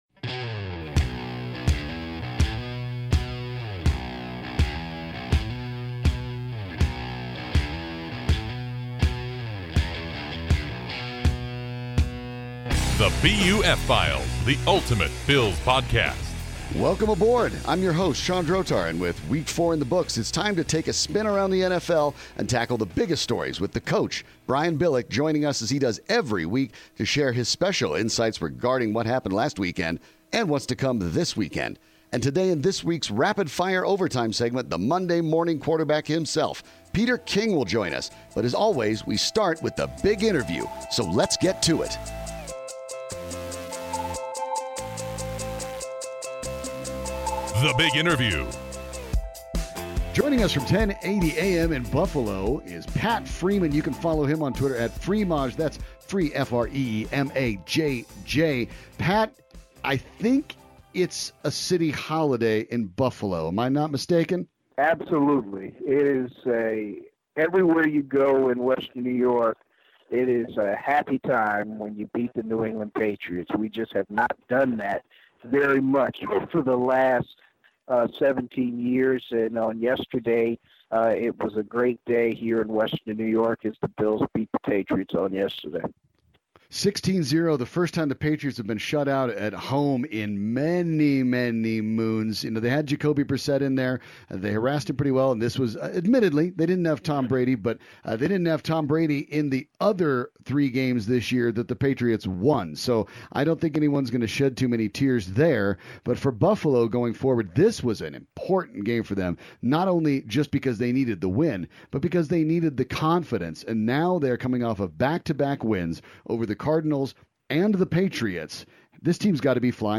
A weekly podcast featuring interviews with members of the Buffalo media, various national NFL personalities, listeners' questions and more.